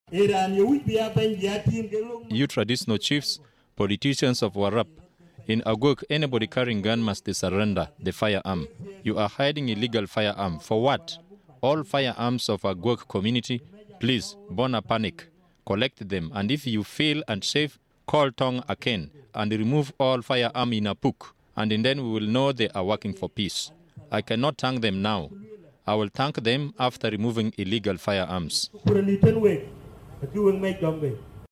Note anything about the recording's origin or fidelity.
He said this at a public rally at Kuacjok freedom square.